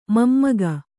♪ mammaga